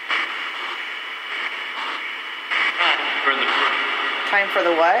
EVPs Captured during Paranormal Investigation
Ghost Box Messages